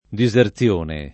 [ di @ er ZL1 ne ]